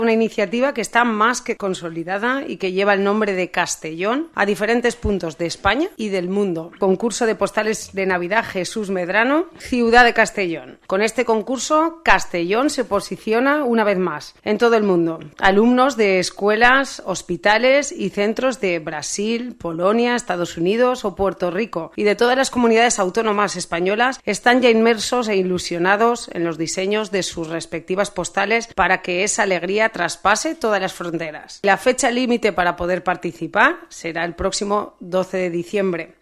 Corte de voz de Noelia Selma, concejal de Fiestas en el Ayuntamiento de Castellón.